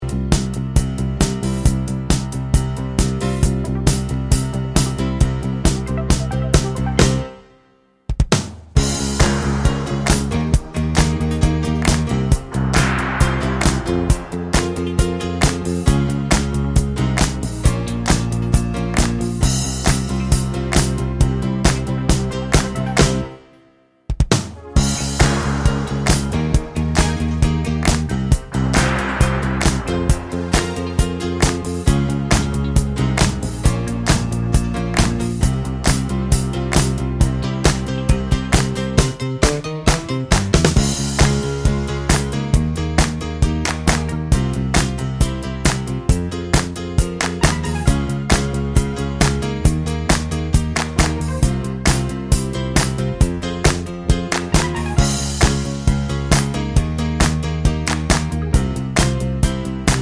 no Backing Vocals and No SFX Pop (1980s) 3:43 Buy £1.50